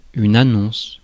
Ääntäminen
IPA: /a.nɔ̃s/